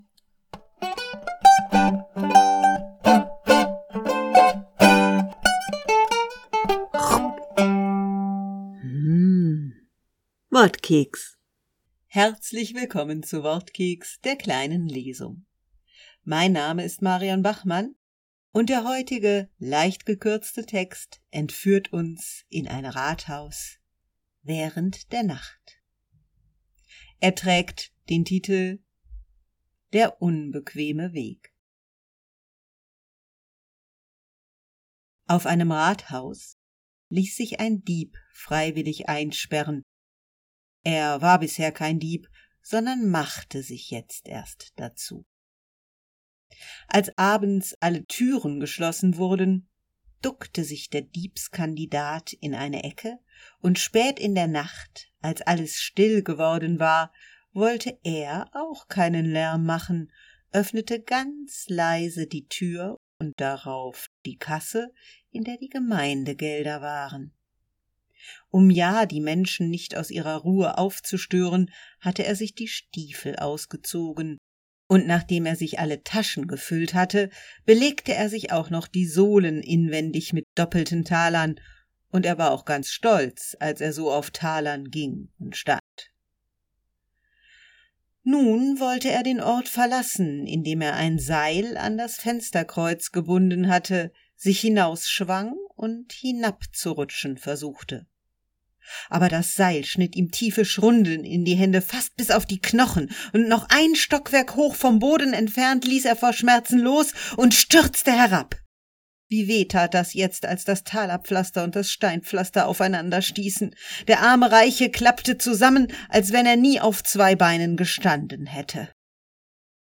Wortkeks - die kleine Lesung